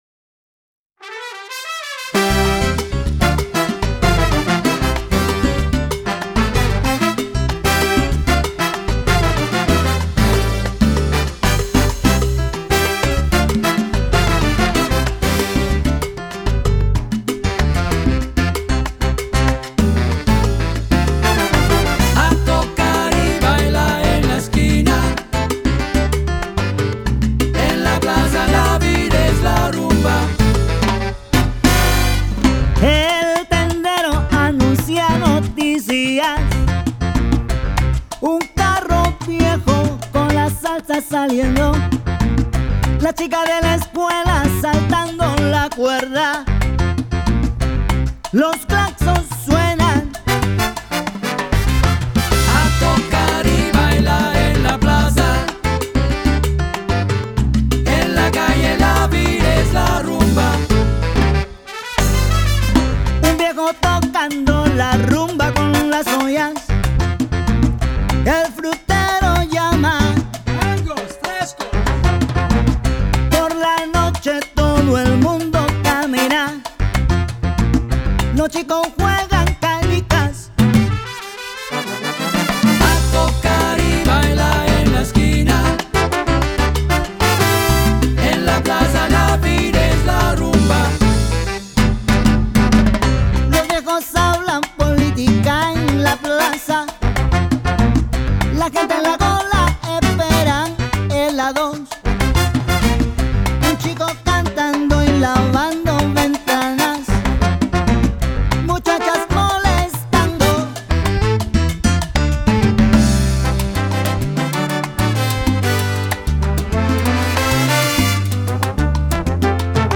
mixing in elements of funk and jazz to create a unique edge.